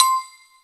AFFRBELLC5-L.wav